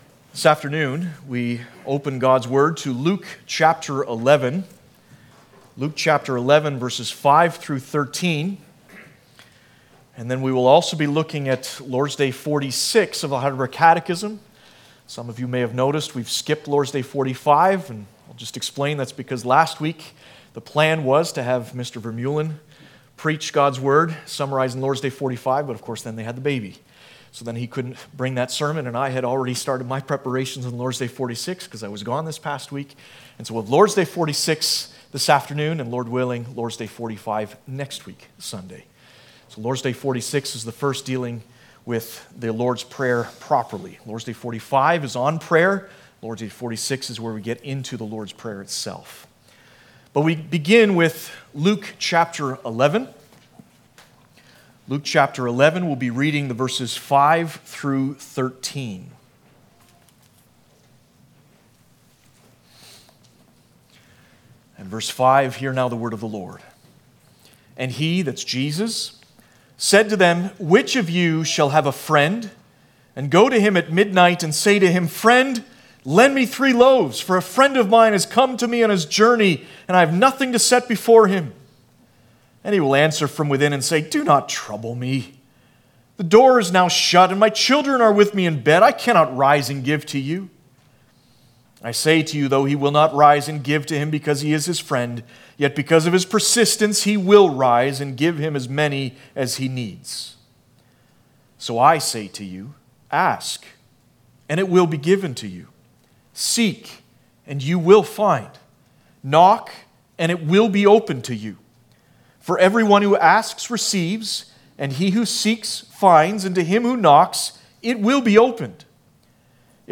Passage: Luke 11:5-13, Lord's Day 46 Service Type: Sunday Afternoon